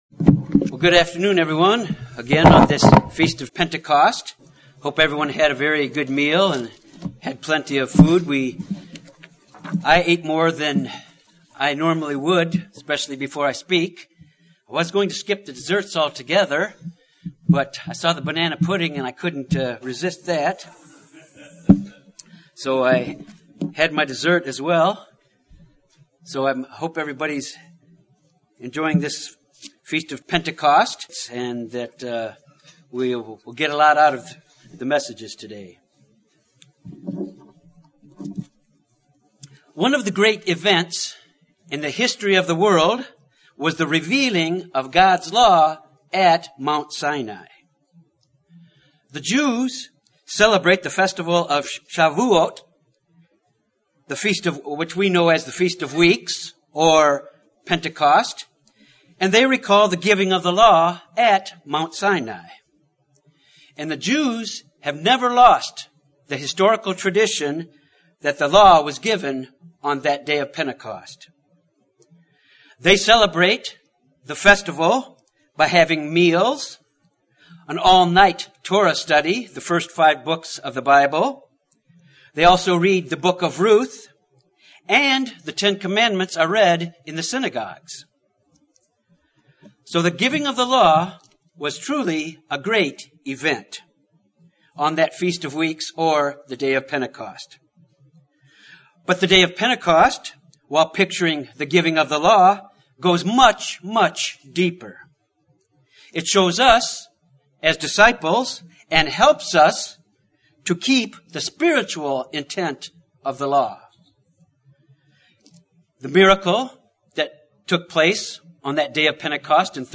This sermon examines why we as human beings need the help of the Holy Spirit to truly worship God and why we, as disciples, need the Holy Spirit.
Given in Little Rock, AR